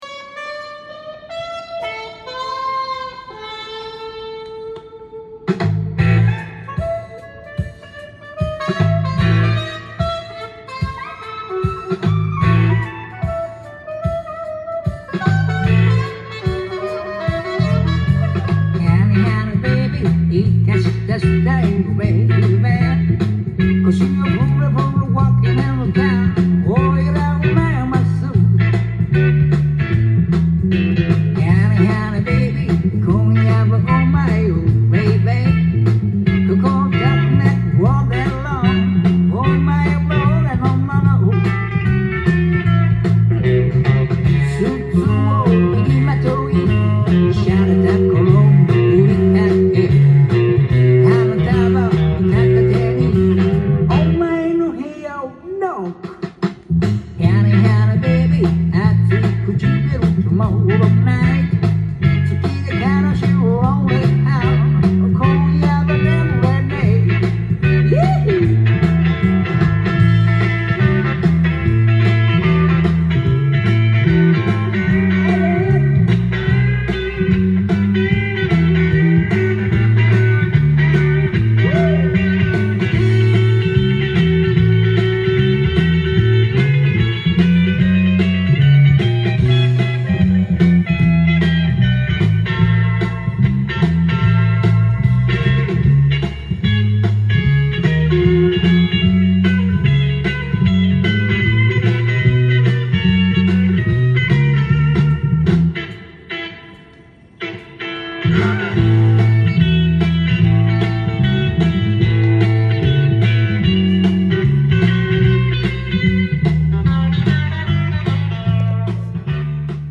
ジャンル：J-BLUES
店頭で録音した音源の為、多少の外部音や音質の悪さはございますが、サンプルとしてご視聴ください。